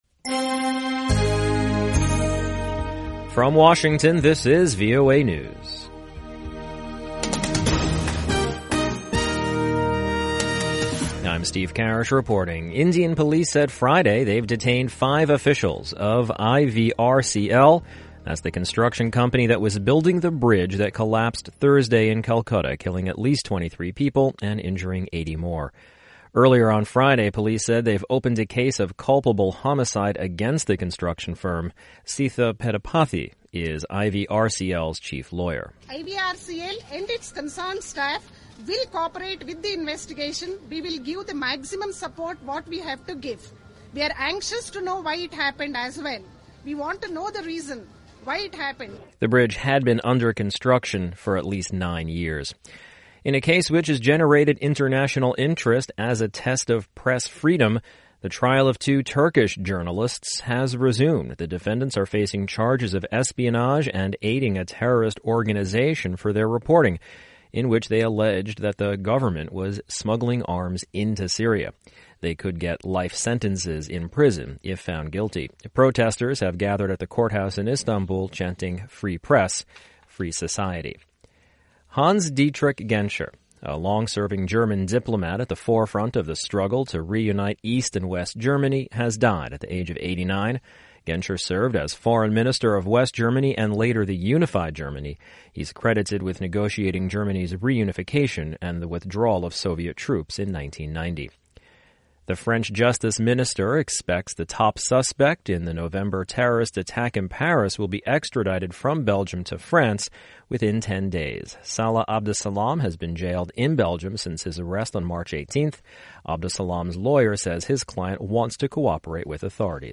VOA English Newscast: 1400 UTC April 1, 2016